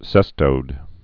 (sĕstōd)